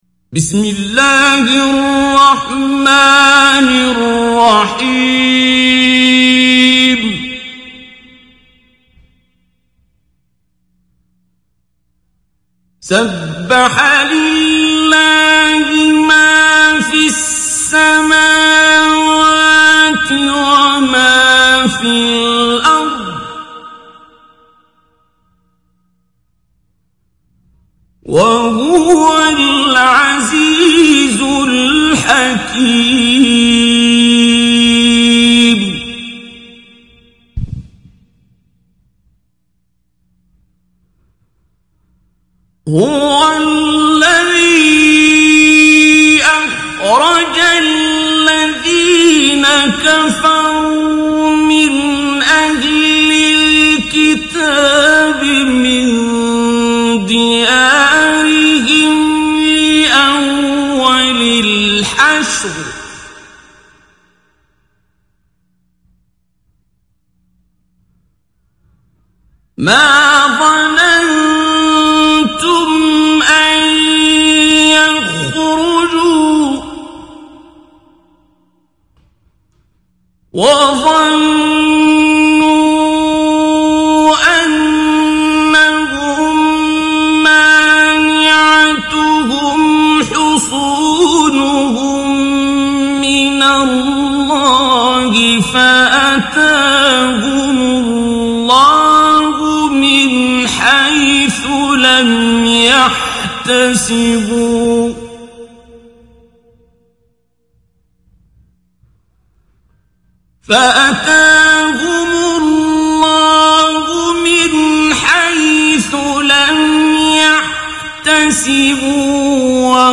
Қуръони карим тиловати, Қорилар. Суралар Qur’oni karim tilovati, Qorilar. Suralar